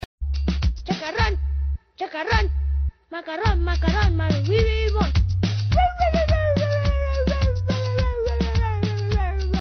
• Качество: 128, Stereo
забавные
веселые
детский голос